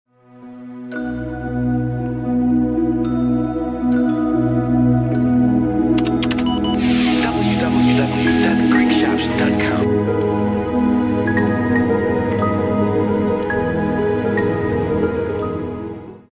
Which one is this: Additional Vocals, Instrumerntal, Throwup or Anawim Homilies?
Instrumerntal